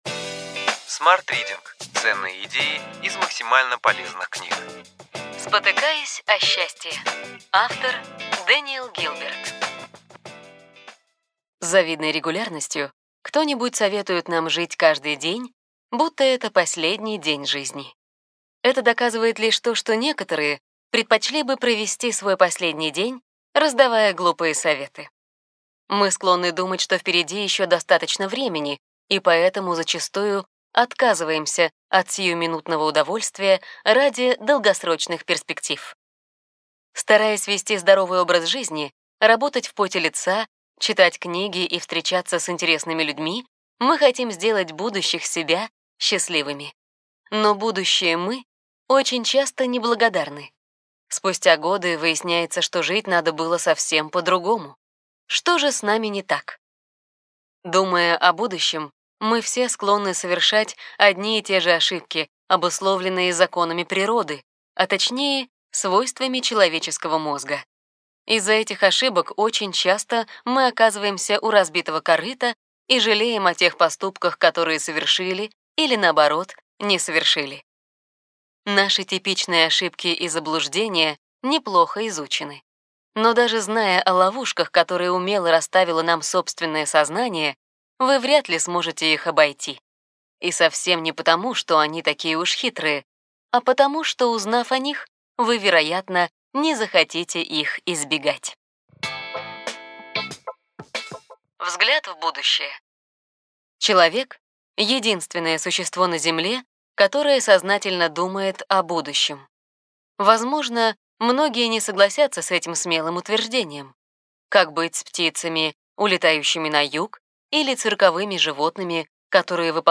Аудиокнига Ключевые идеи книги: Спотыкаясь о счастье.